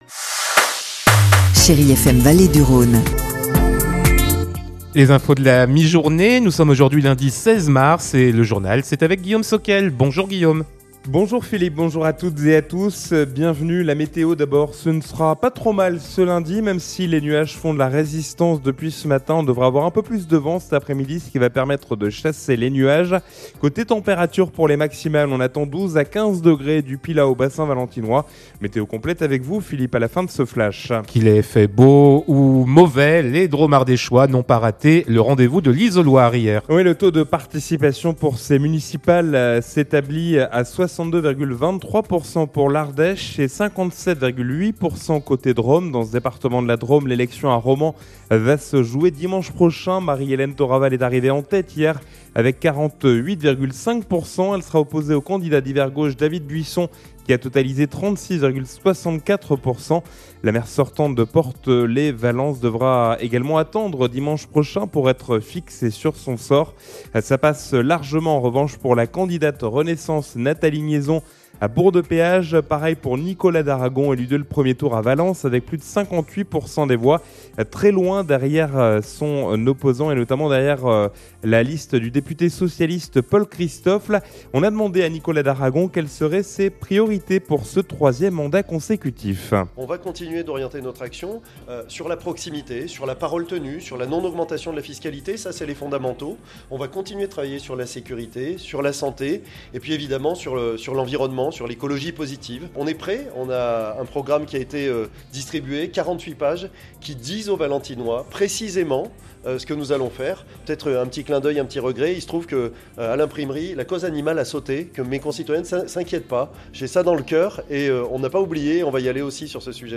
Lundi 16 mars : Le journal de 12h